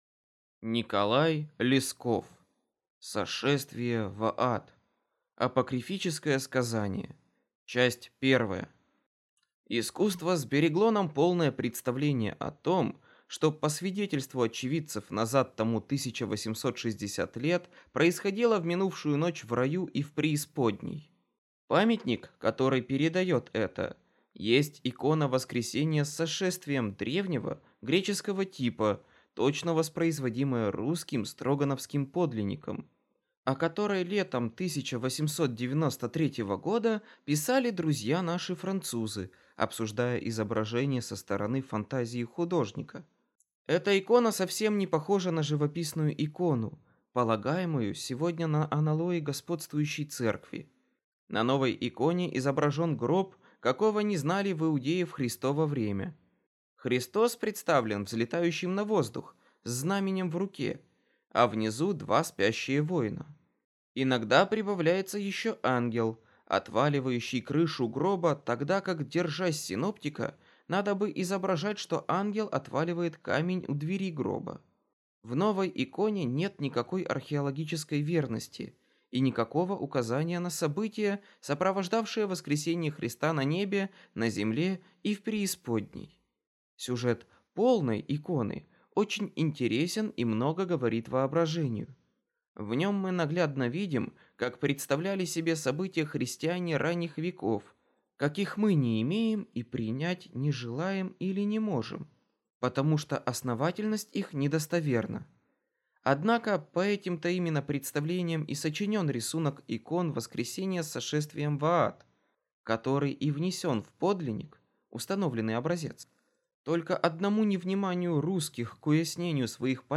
Аудиокнига Сошествие в ад | Библиотека аудиокниг